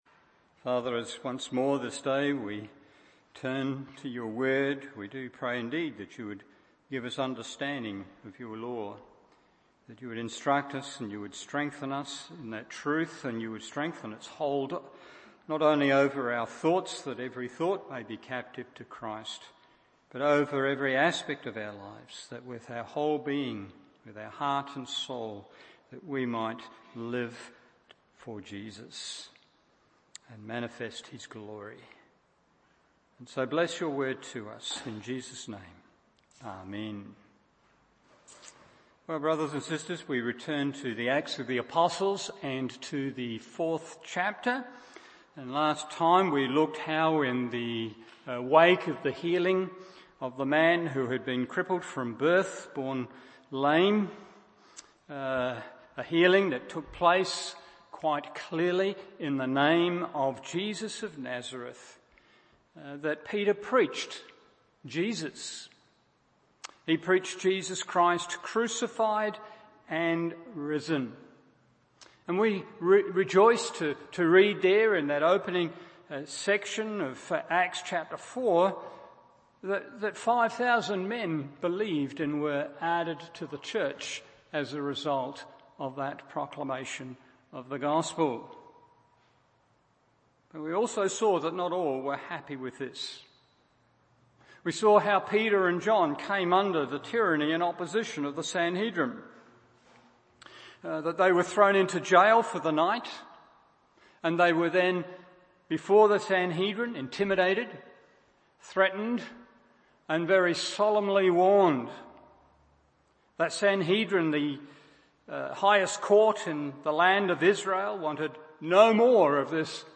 Evening Service Acts 4:23-27 1.